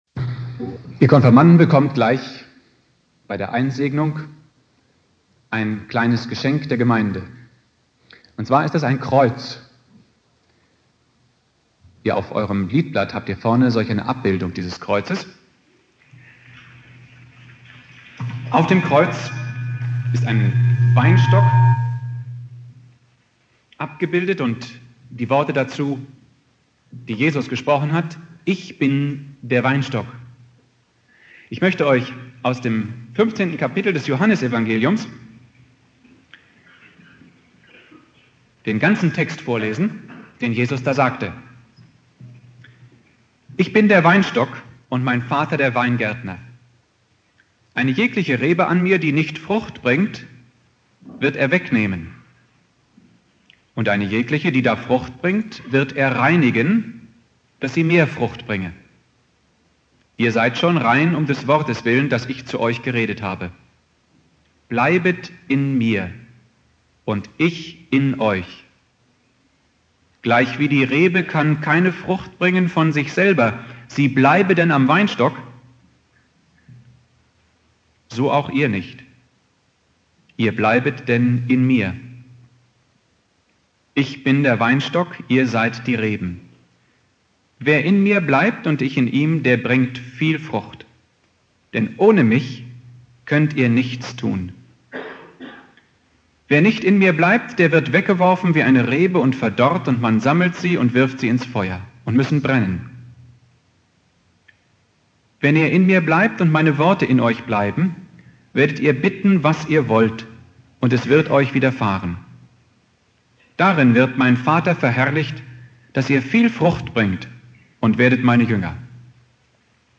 Predigt
"Ich bin der Weinstock" (Konfirmation Hausen) Bibeltext